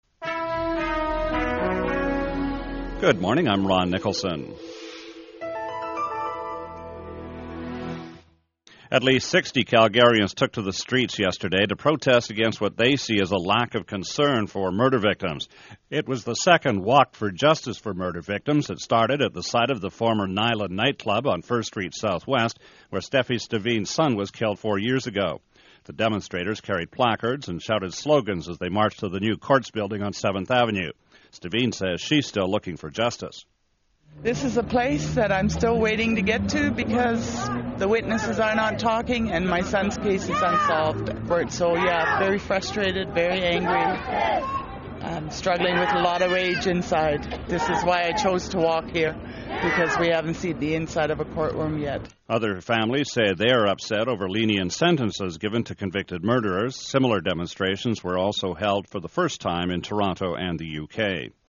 CBC Radio News clips, September 9th 2007: